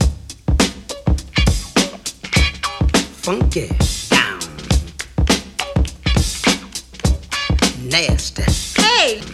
• 103 Bpm Modern Drum Groove C# Key.wav
Free drum groove - kick tuned to the C# note. Loudest frequency: 1782Hz
103-bpm-modern-drum-groove-c-sharp-key-XcU.wav